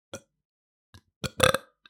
Burps burping - is this a vocal
Category 🗣 Voices
breathy female funny male mouth sound speaking speech sound effect free sound royalty free Voices